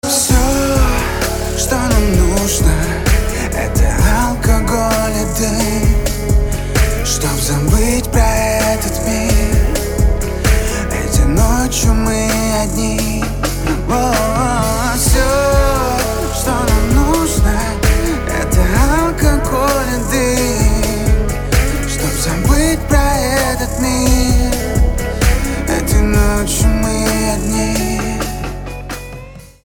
мужской голос
dance
спокойные
чувственные
медленные
медляк
танцевальные